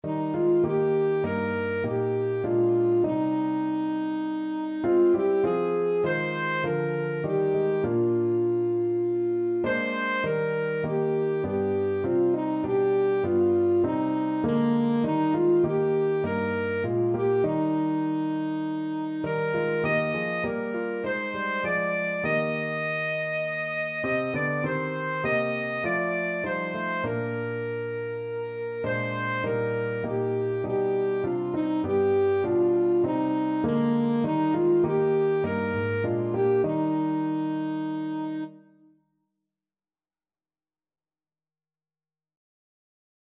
Christian
Alto Saxophone
4/4 (View more 4/4 Music)
Classical (View more Classical Saxophone Music)